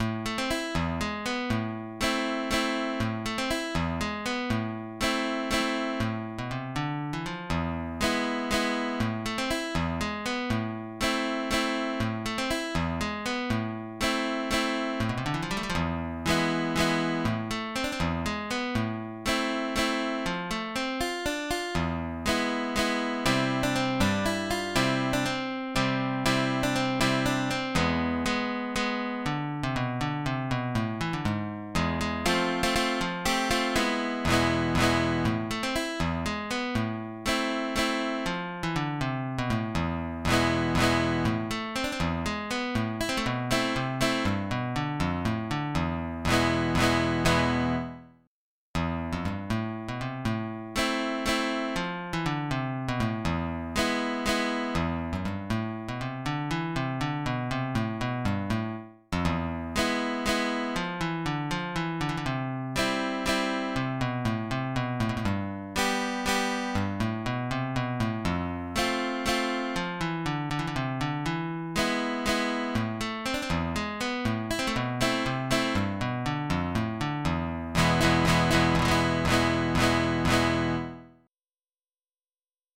Brani da non perdere (Flamenco):
alba_op85_Las-Nuevas-Peteneras.mid.mp3